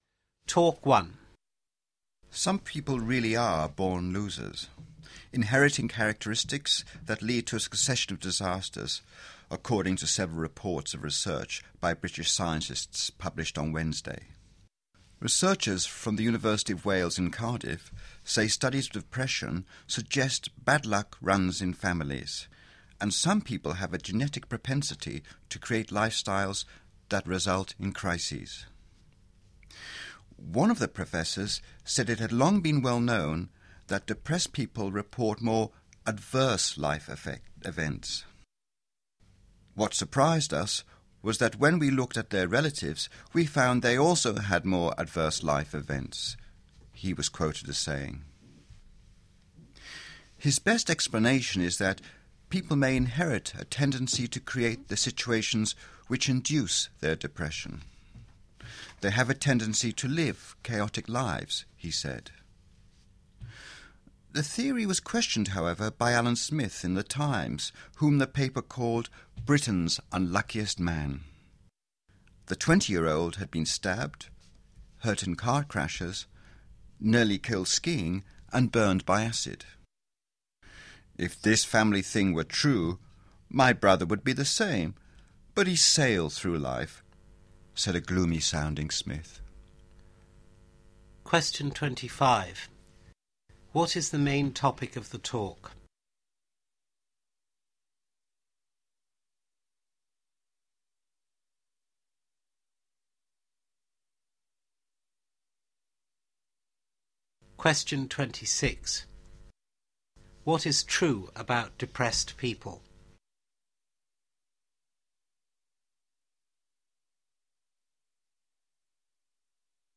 Monologue 3-5 นาทีที่แสนจะท้อ?
หลายคนเกลียดกลัว Monologue มากๆ เพราะฟังยาวครับ ทีนี้ตามชื่อเลย Mono คือ 1 ดังนั้นจะมีคนเพียง 1 คน มาพูดให้เราฟัง ซึ่งก็แน่นอนครับ เป็นเหมือนการอ่าน Article ให้เราฟัง ซึ่งค่อนข้างวิชาการทีเดียว